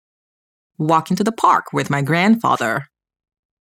ˈwɑː kɪŋ tə ðə pɑːrk wɪð maɪ ˈɡræn fɑː ðɚ